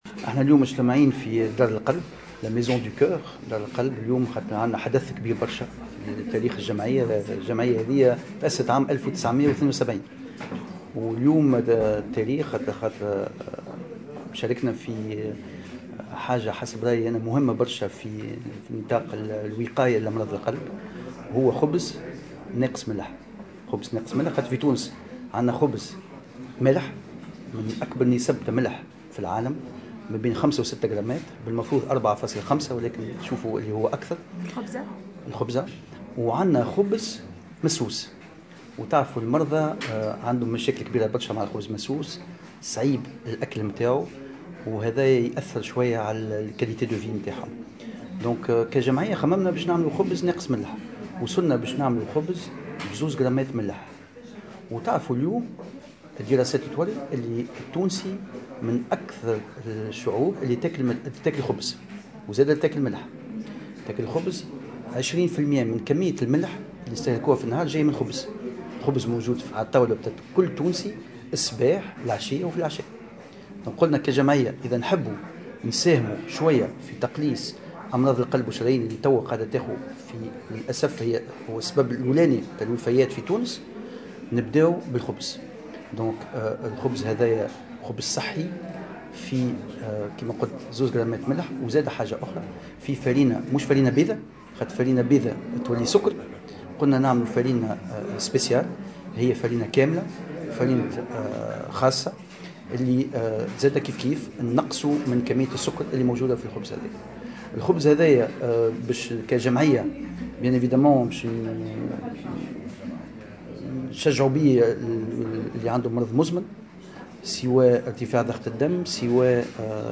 في تصريح لمراسلة الجوهرة أف أم، على هامش اجتماع لجمعية أمراض القلب والشرايين بدار القلب